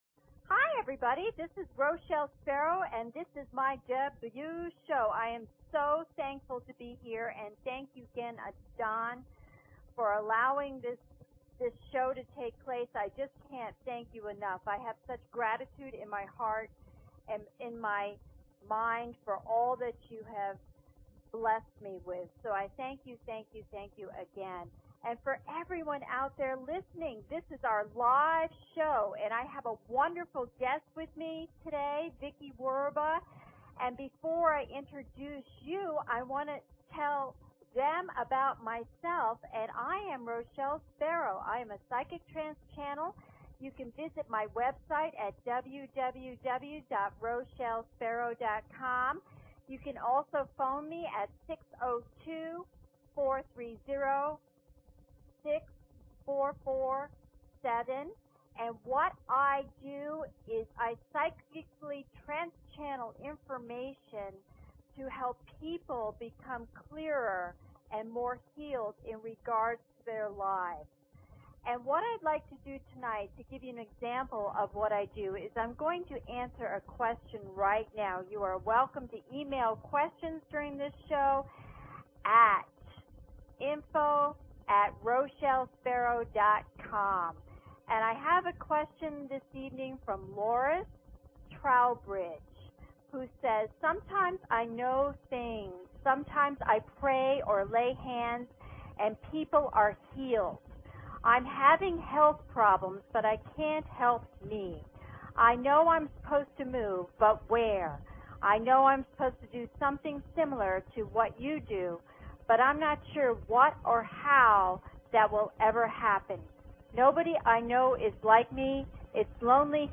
Talk Show Episode, Audio Podcast, Psychic_Connection and Courtesy of BBS Radio on , show guests , about , categorized as